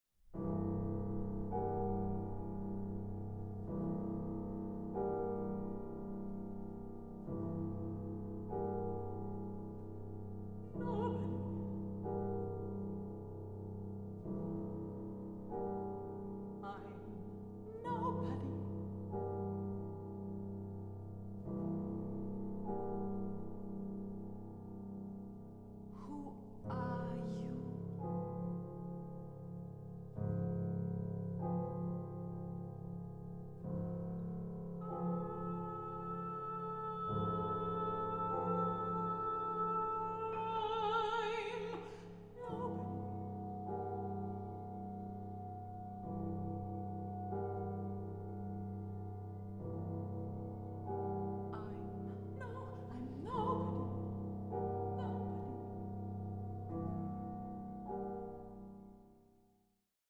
Sopran
Klarinette
Klavier